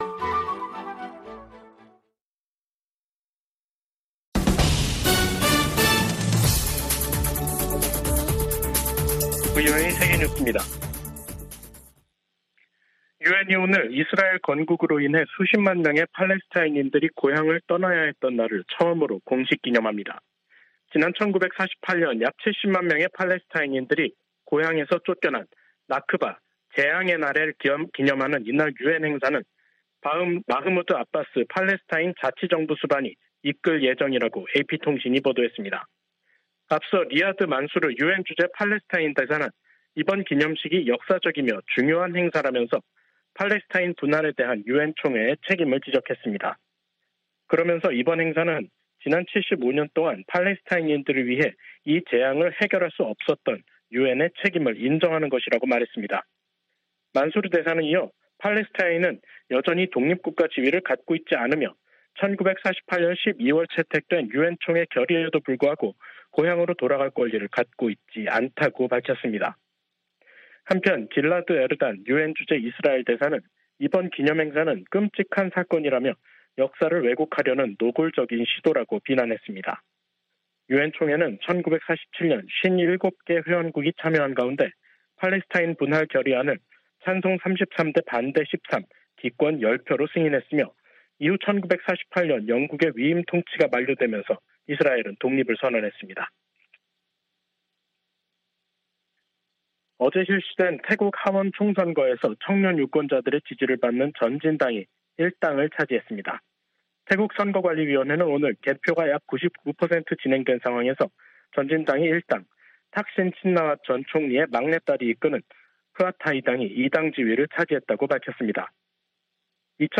VOA 한국어 간판 뉴스 프로그램 '뉴스 투데이', 2023년 5월 15일 2부 방송입니다. 윤석열 한국 대통령이 일본에서 열리는 G7 정상회의를 계기로 서방 주요국 지도자들과 회담하고 강한 대북 메시지를 낼 것으로 보입니다. G7 정상회의에 참석하는 캐나다와 유럽연합(EU), 독일 정상들이 잇따라 한국을 방문해 윤 대통령과 회담합니다. 북한은 다양한 핵탄두 개발을 위해 추가 핵실험을 하게 될 것이라고 미국의 전문가가 밝혔습니다.